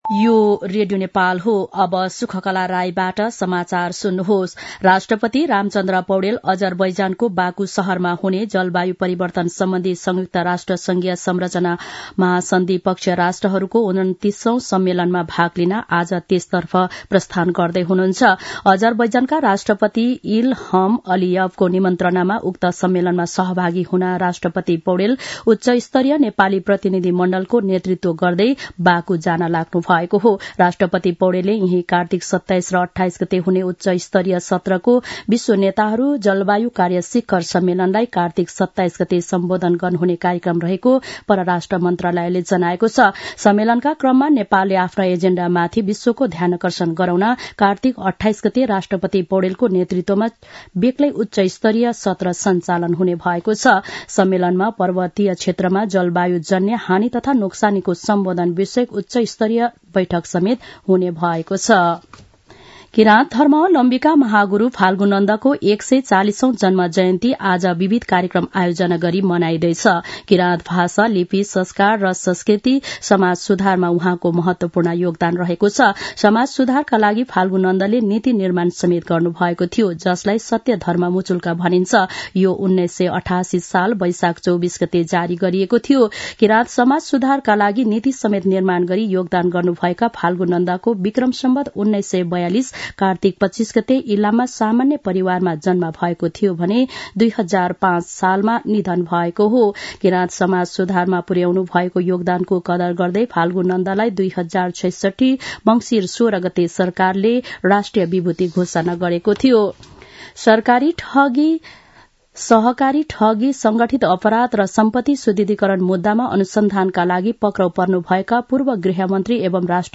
दिउँसो १ बजेको नेपाली समाचार : २६ कार्तिक , २०८१